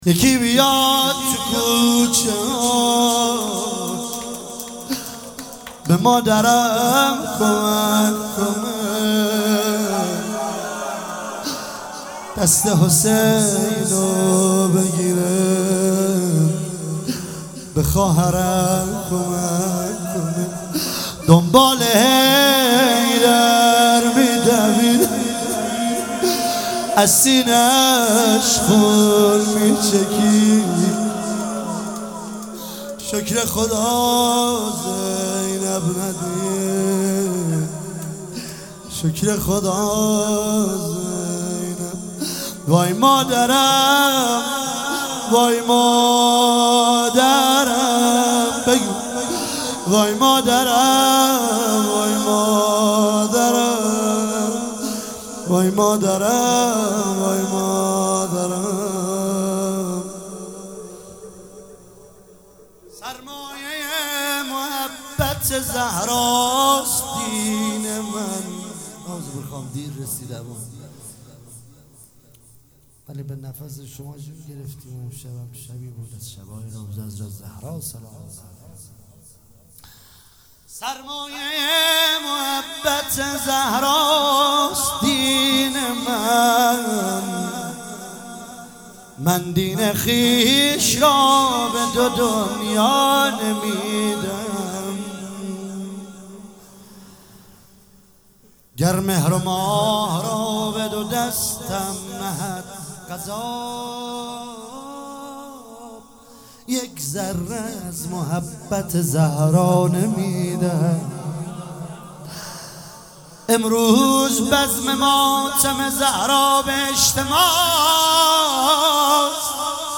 فاطمیه اول ۱۳۹۶
کرمانشاه
روضه پایانی یکی بیاد تو کوچه ها